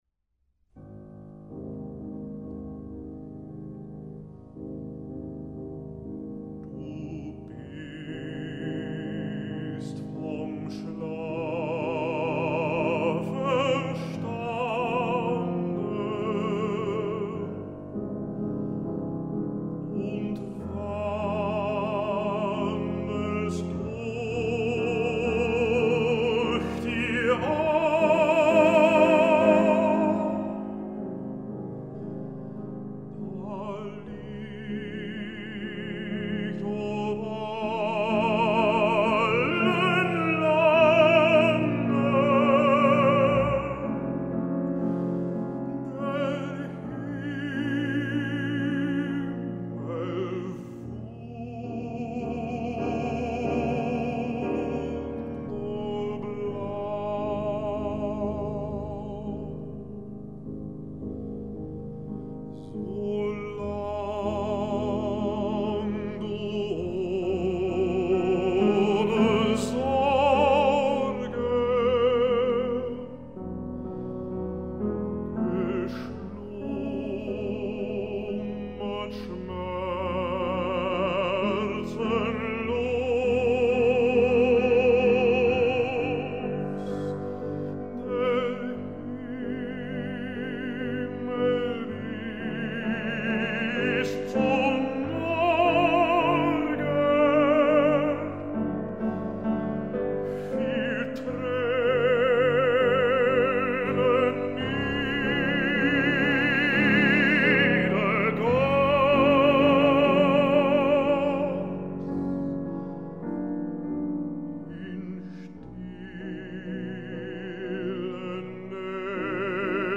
baríton
piano